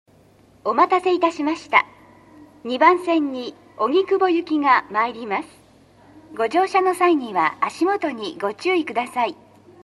B線ホームで収録すると、真上にA線の線路があるのでよくガタンゴトンと被ります・・。